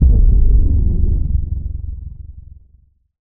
Index of /musicradar/impact-samples/Low End
Low End 02.wav